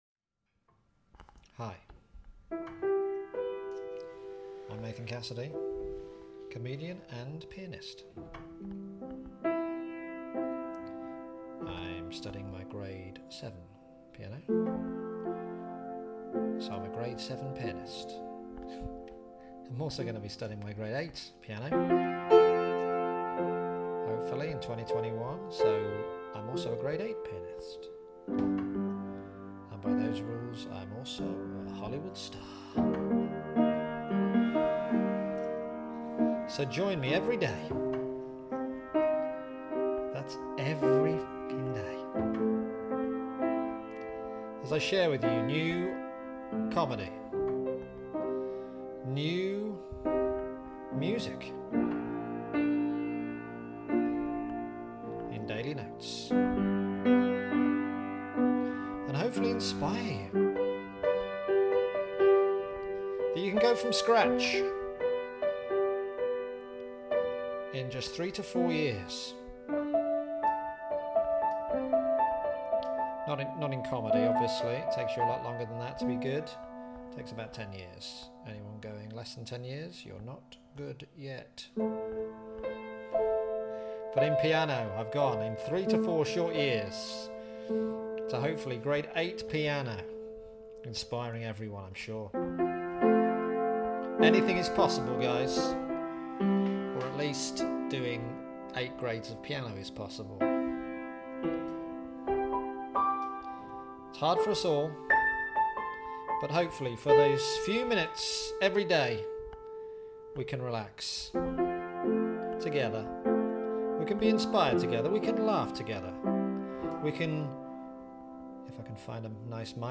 Daily Notes on piano and comedy from award-winning comedian and trainee pianist